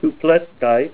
Say KUPLETSKITE-(CS) Help on Synonym: Synonym: Cesium-kupletskite   IMA2004-04a